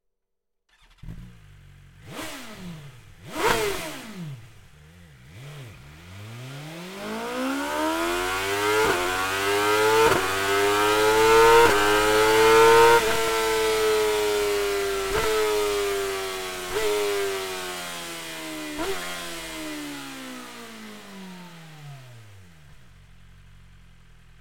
Akrapovič Slip-On Endschalldämpfer (Carbon) S 1000 R / M 1000 R | Wunderlich